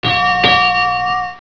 Destroyer Siren (genuine WW2 recording of a Destroyer Whooping siren) -ASDIC (Acoustic anti-submarine pinging) -Ships bell (automatic activation every 60 seconds).